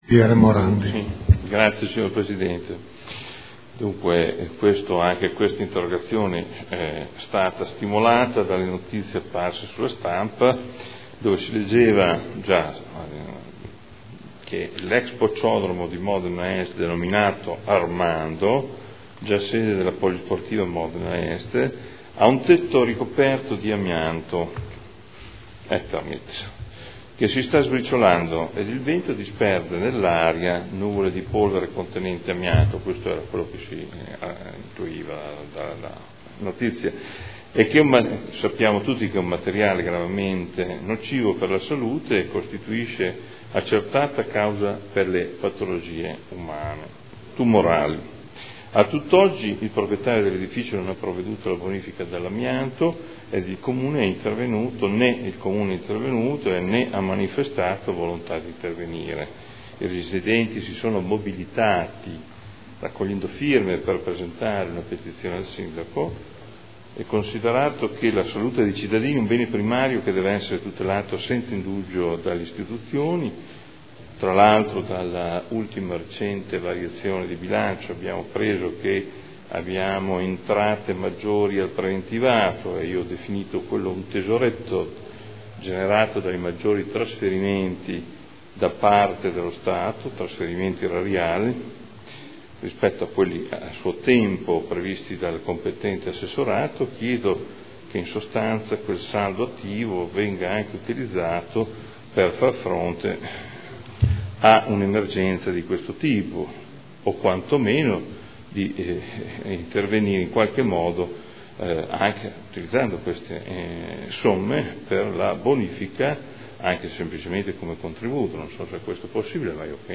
Adolfo Morandi — Sito Audio Consiglio Comunale